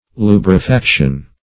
Search Result for " lubrifaction" : The Collaborative International Dictionary of English v.0.48: Lubrification \Lu`bri*fi*ca"tion\, Lubrifaction \Lu`bri*fac"tion\, n. [L. lubricus lubric + facere to make.] The act of lubricating, or making smooth.
lubrifaction.mp3